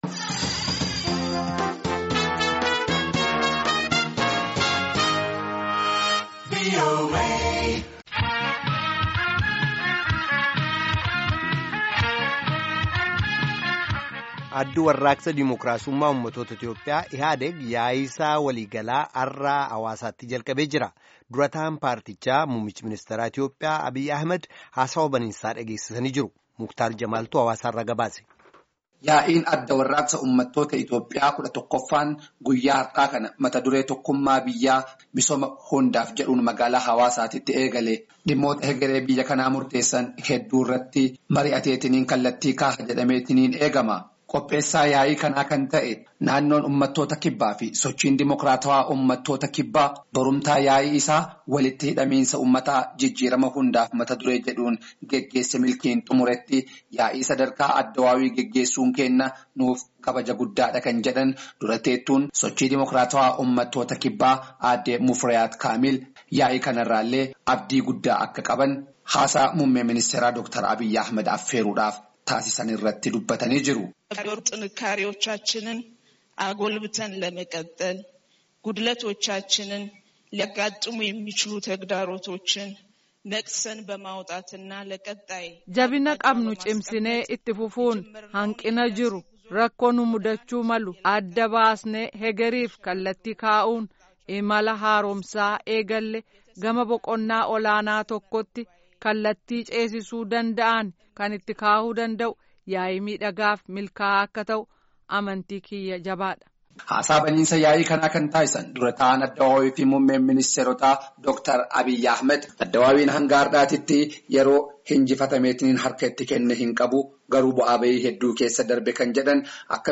Gabaasaa guutuu